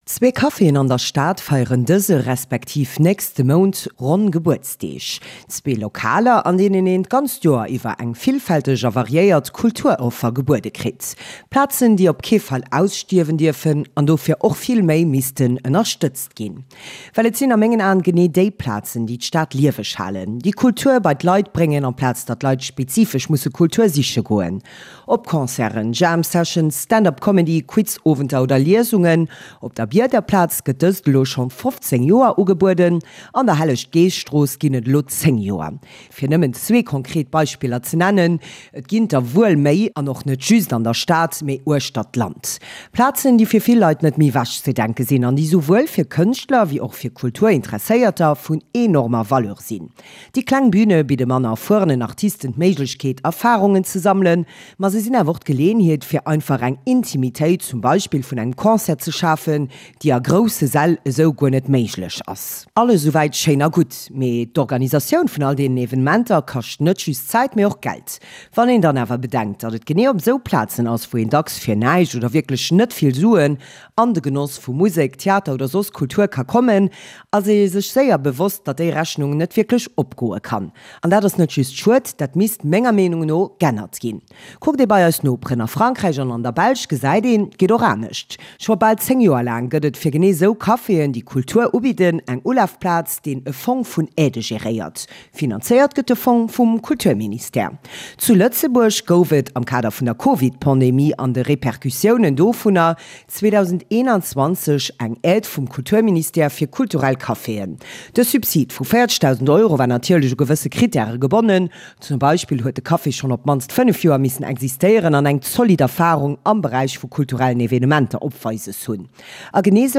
Commentaire Podcast